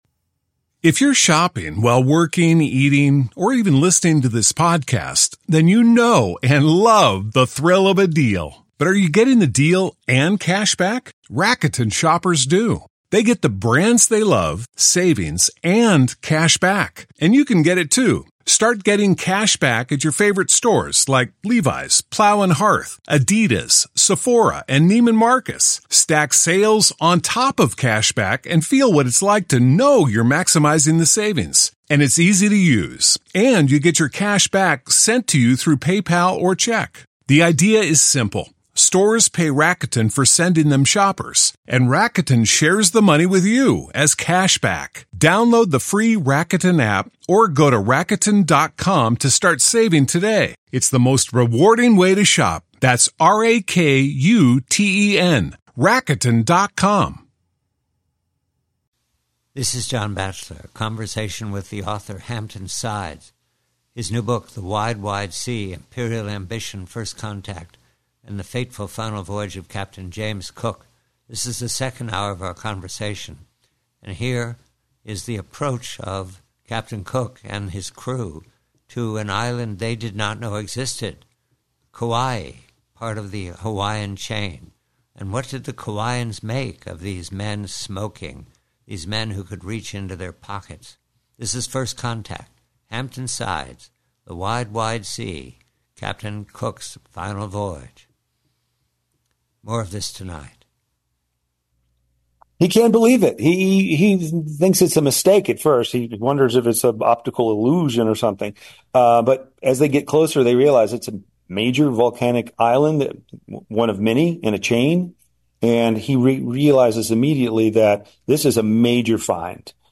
PREVIEW: COOK: HAWAII: Conversation with author Hampton Sides, "The Wide, Wide Sea," re: the first contact between the Royal Navy led by Captain James Cook and the Hawaiians, 1778.